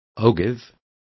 Complete with pronunciation of the translation of ogives.